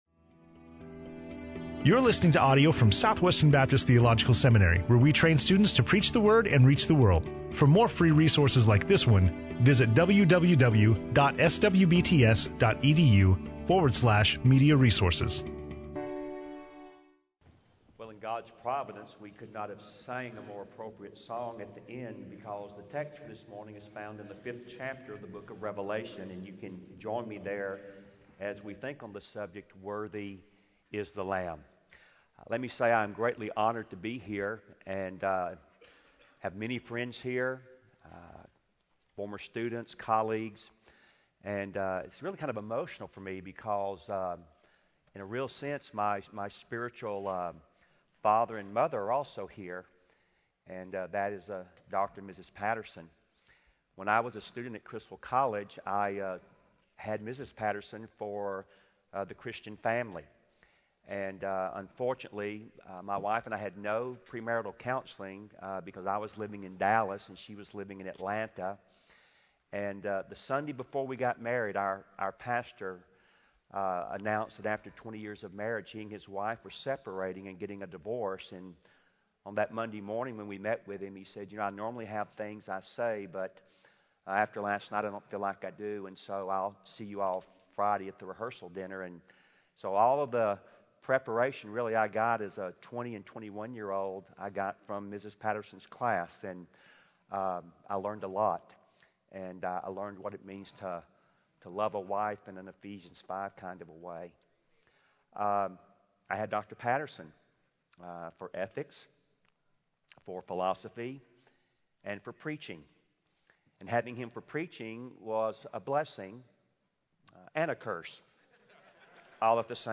SWBTS Chapel
SWBTS Chapel Sermons